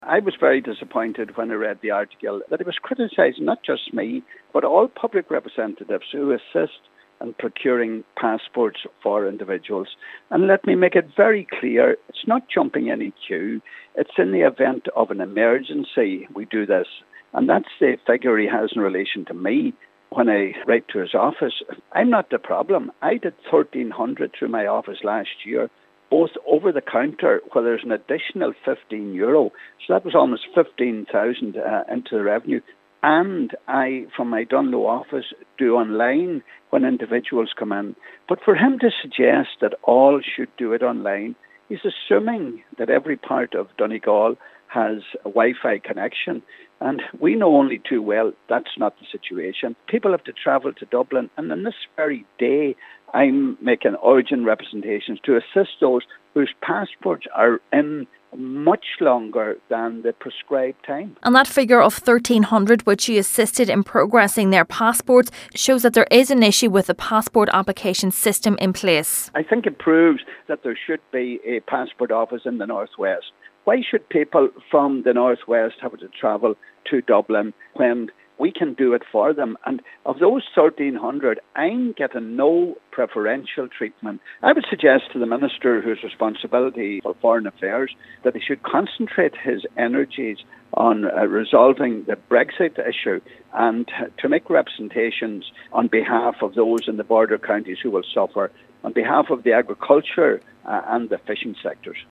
He says the Minister would be better suited to focusing his work elsewhere: